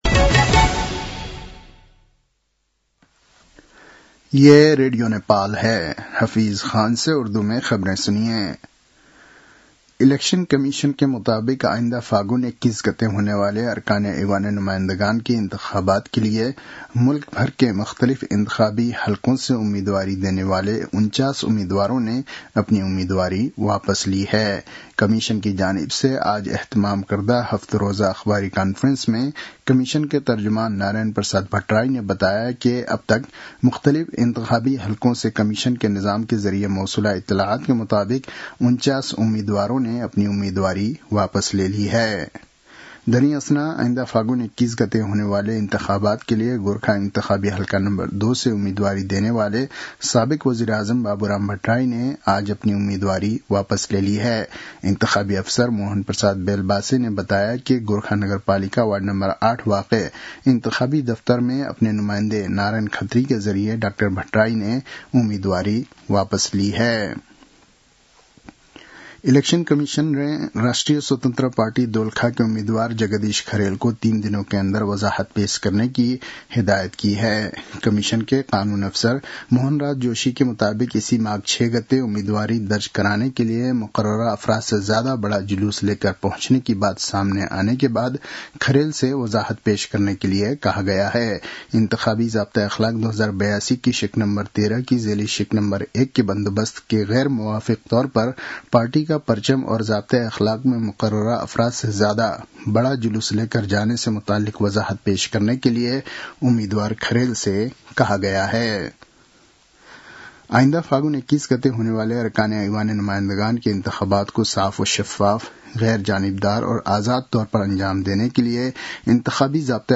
उर्दु भाषामा समाचार : ९ माघ , २०८२
Urdu-news-10-09.mp3